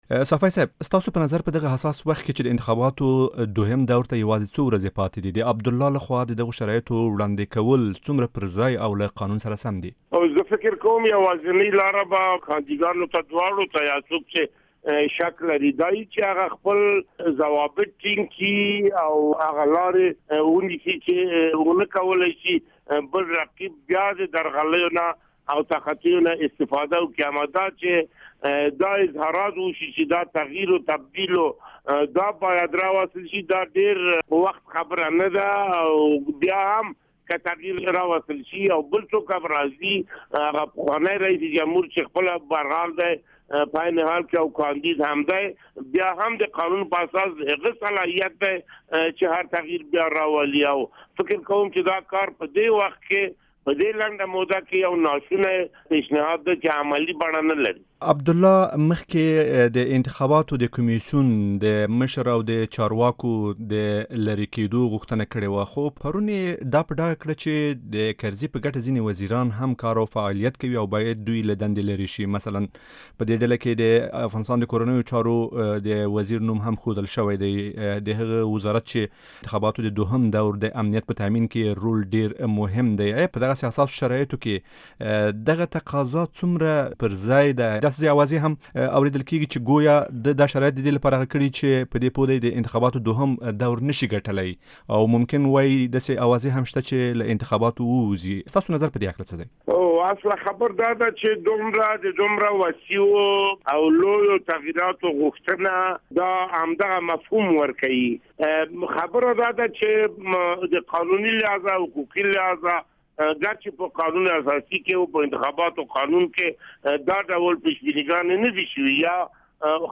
د ازادۍ راډیو مرکه